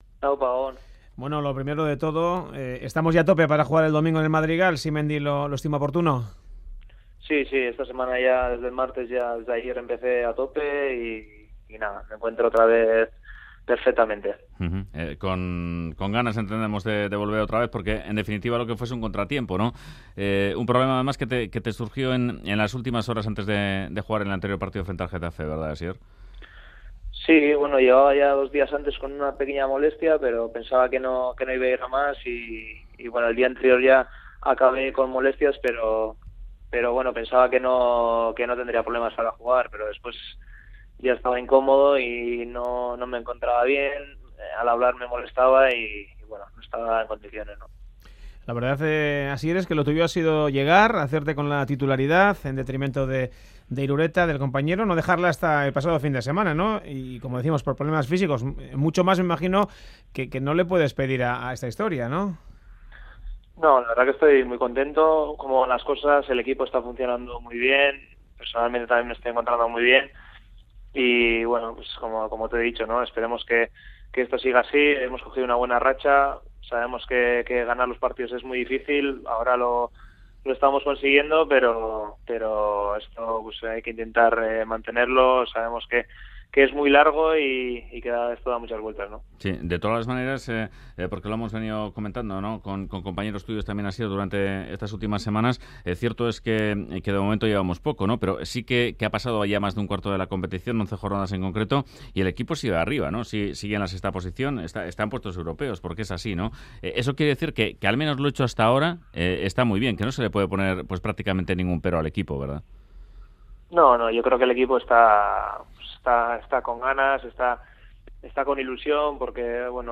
Entrevista al portero del Eibar Asier Riesgo, antes de ir a Villarreal